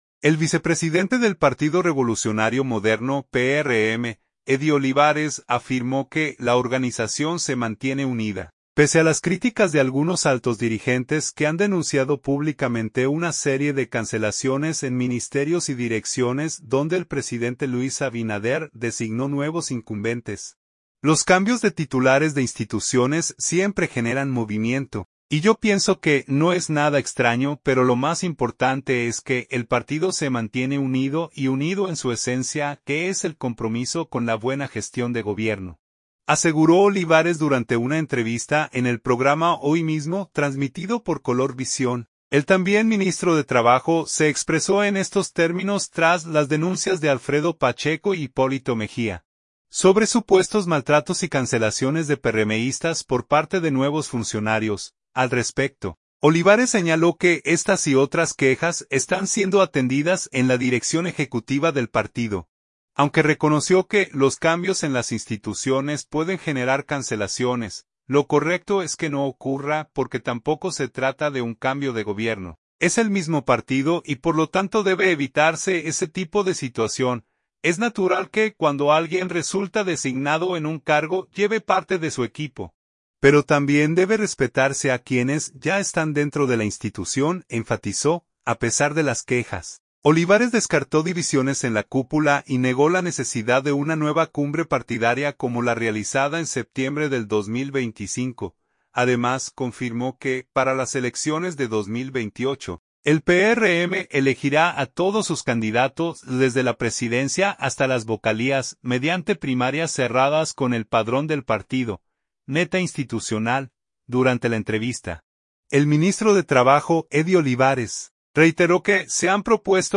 “Los cambios de titulares de instituciones siempre generan movimiento, y yo pienso que no es nada extraño, pero lo más importante es que el partido se mantiene unido, y unido en su esencia, que es el compromiso con la buena gestión de gobierno”, aseguró Olivares durante una entrevista en el programa Hoy Mismo, transmitido por Color Visión.